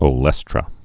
(ō-lĕstrə)